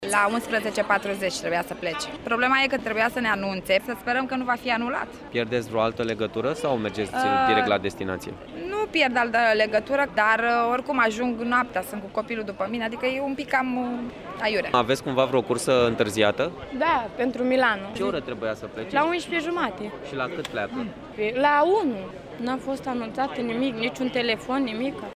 Pasagerii sunt nemulţumiţi de întârzierile mari: